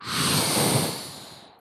portalsound.mp3